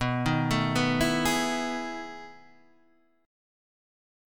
B13 chord {7 6 7 9 9 9} chord